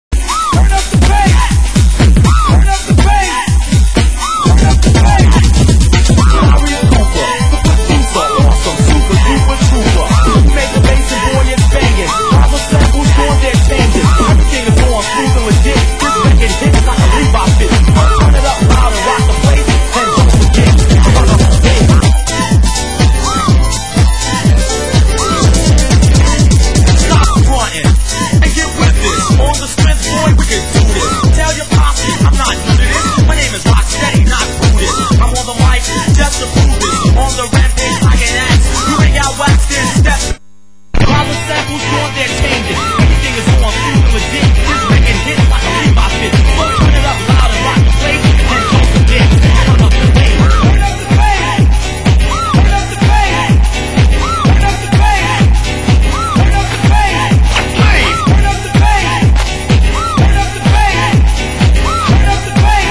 Genre Acid House